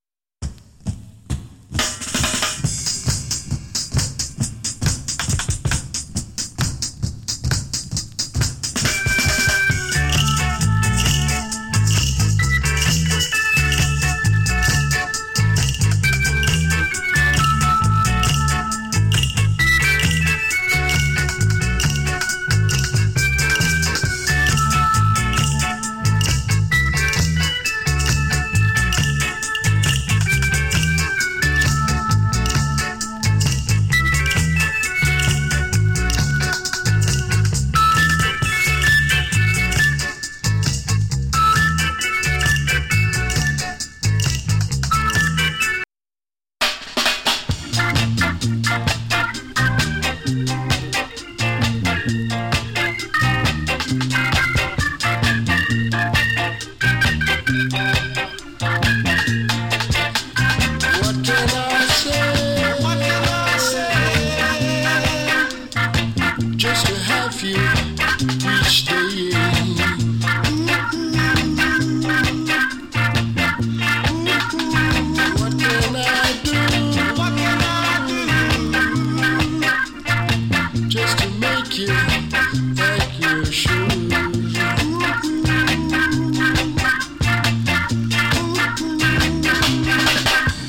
70年 GREAT ORGAN INST !! KILLER SKINHEAD REGGAE !!